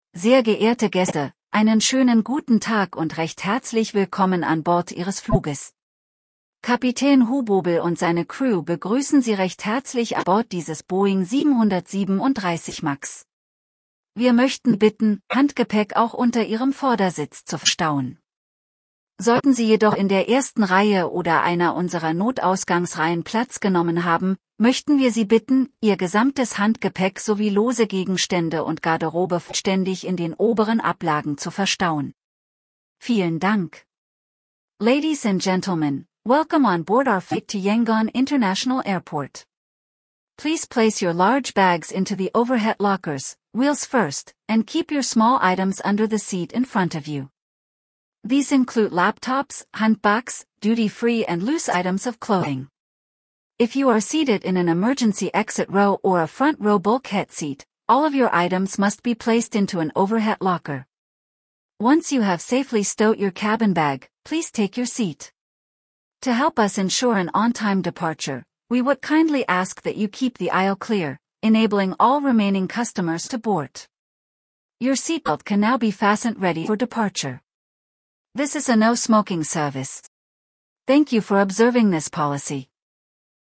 BoardingWelcome.ogg